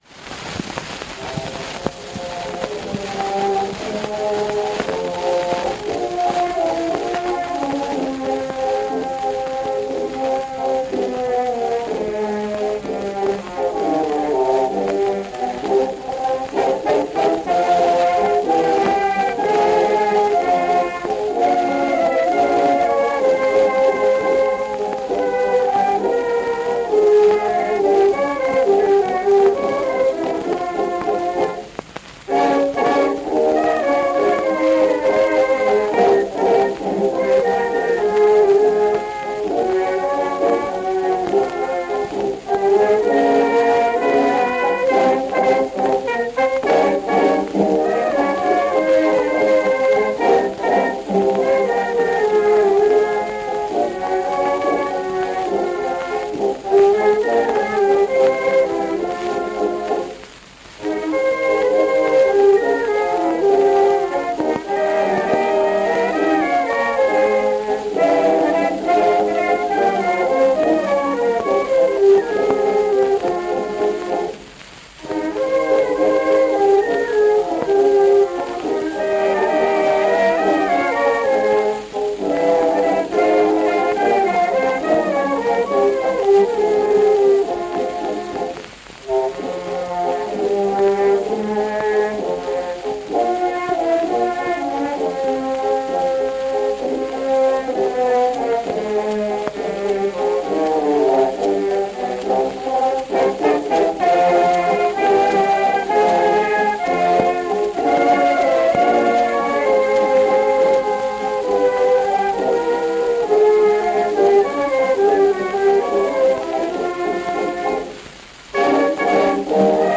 Тот вальс